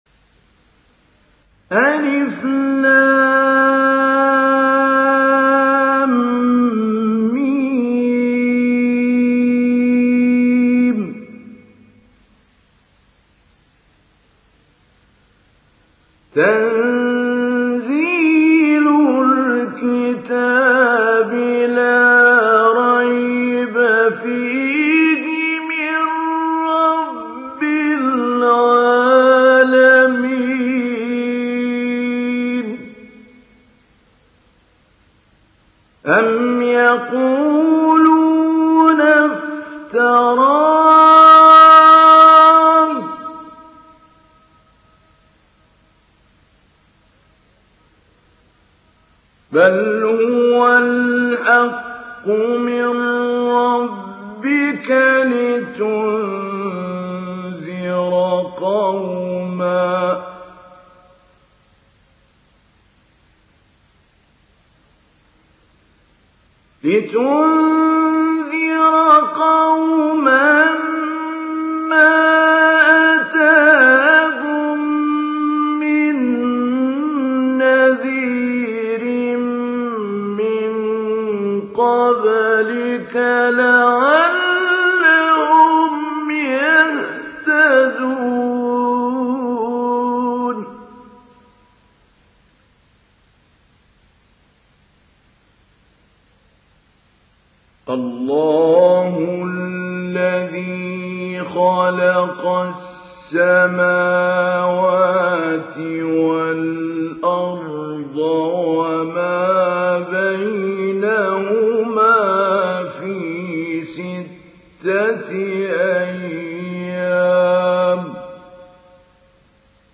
ডাউনলোড সূরা আস-সাজদা Mahmoud Ali Albanna Mujawwad